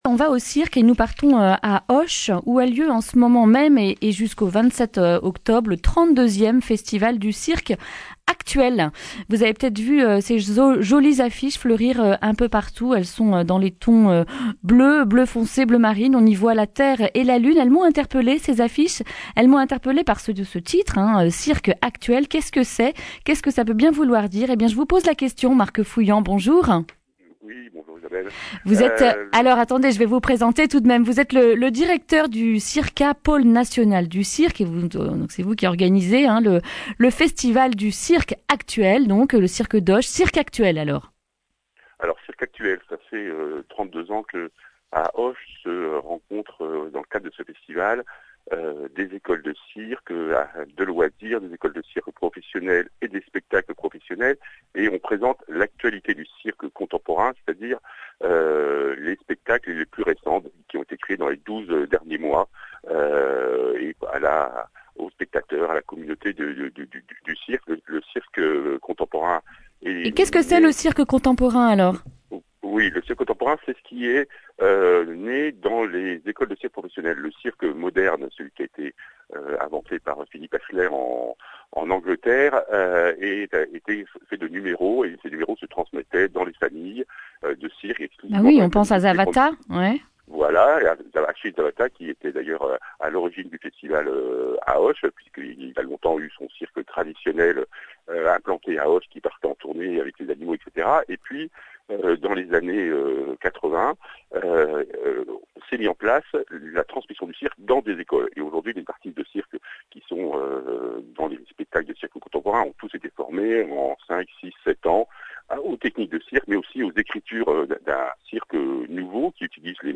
mardi 22 octobre 2019 Le grand entretien Durée 11 min